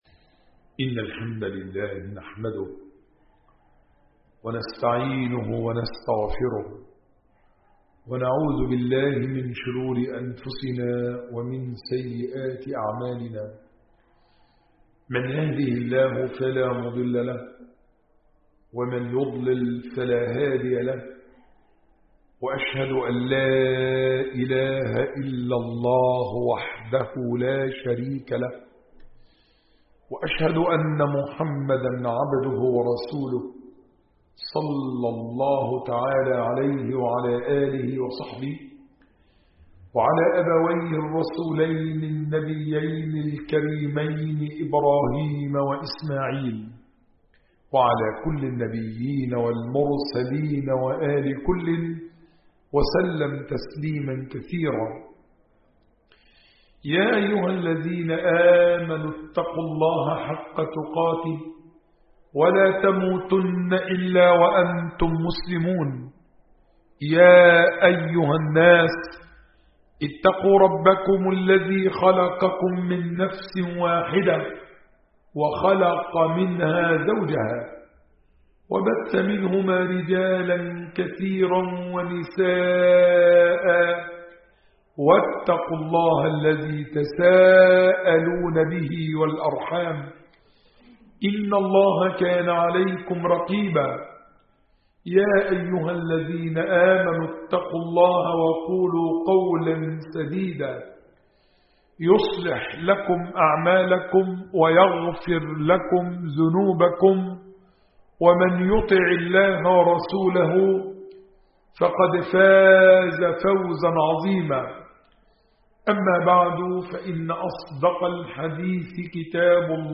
خطبة
22 مارس 2024 م 9.5M 00:20:48 مواعظ ورقائق 104 6 باقي خطب الشيخ كل الخطب سماع الخطبة تحميل الخطبة شارك